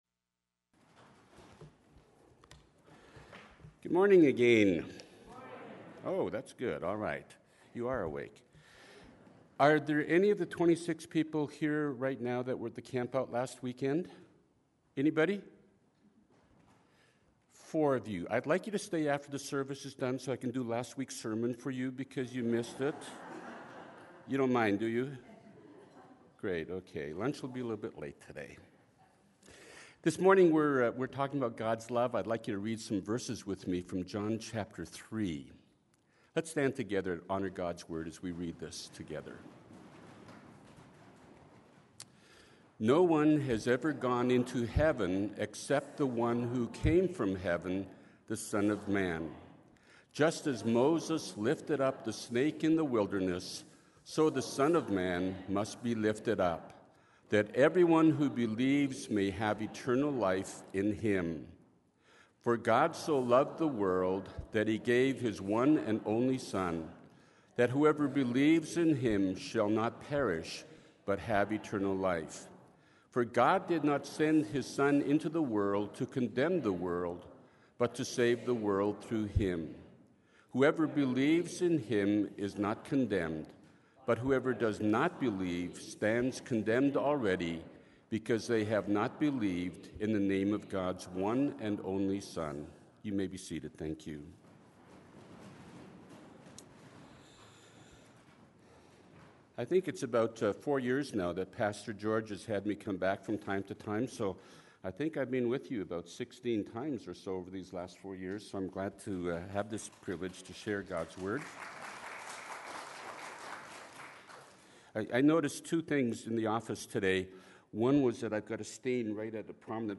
Listen to Sunday sermons online and download the Order of Service.